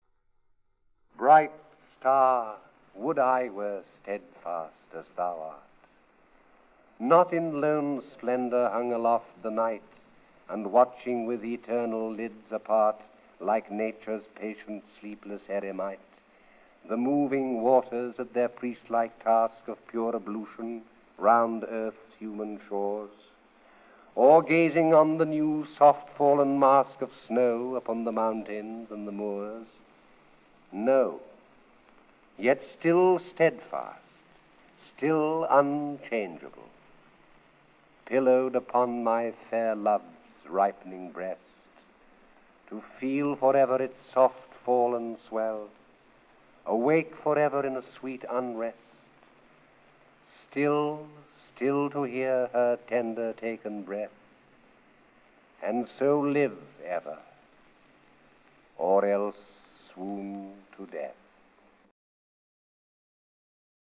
When his health made it difficult for him to pursue acting roles, he began to record favourite poems in his home.